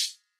Closet Hat
Rose Tinted Cheeks Hat.wav